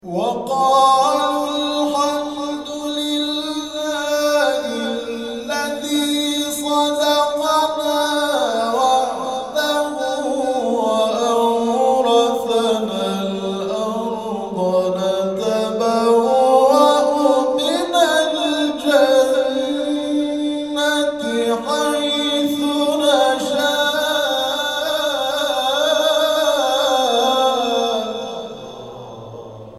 گروه جلسات و محافل: کرسی های تلاوت نفحات القرآن طی هفته گذشته در مساجد الزهراء(س) تهران و مسجد جامع امام رضا(ع) شهرری برگزار شد.